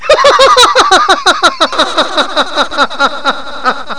1 channel
laugh4.mp3